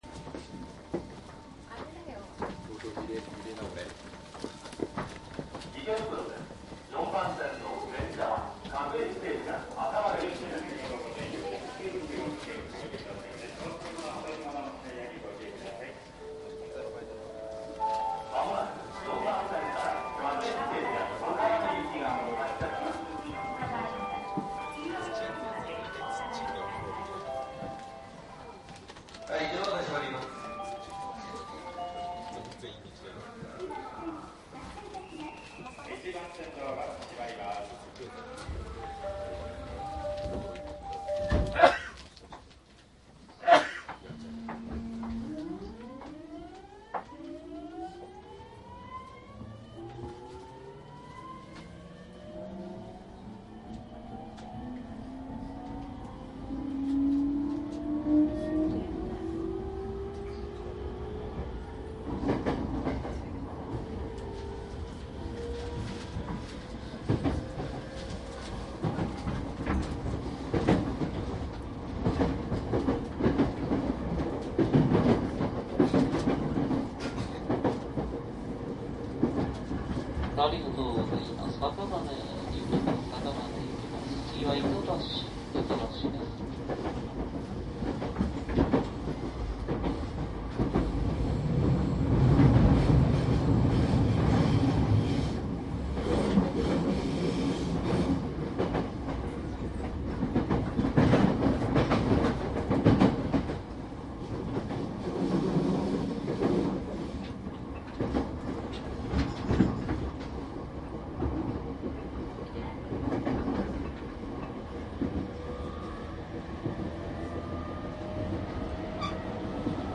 JR埼京線205系走行音♪
りんかい線内のみの録音に加えて、通勤快速にて川越まで収録しています。
■【横須賀線から直通→各駅停車】大崎→新宿→赤羽
E217→70-000系 ＜DATE02－12－2＞
マスター音源はデジタル44.1kHz16ビット（マイクＥＣＭ959）で、これを編集ソフトでＣＤに焼いたものです。